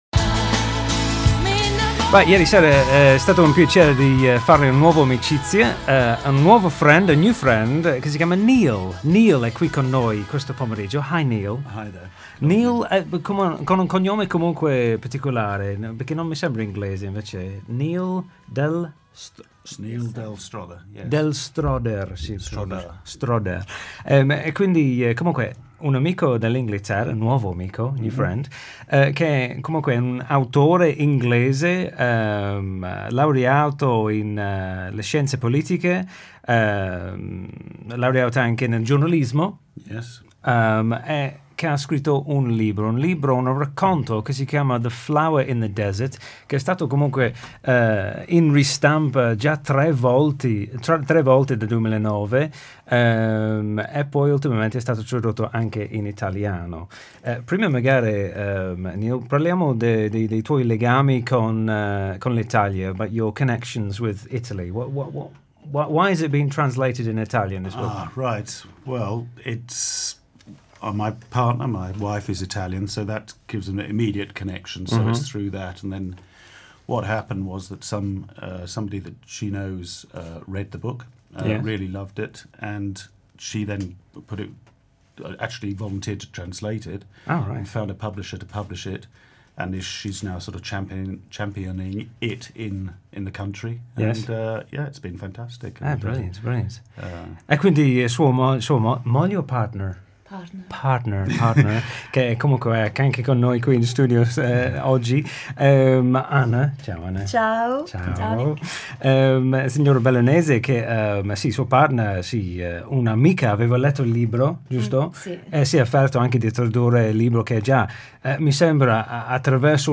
Ascolta una conversazione alla radio su questo bel racconto Consiglio d'Europa, Quadro comune europeo di riferimento per le lingue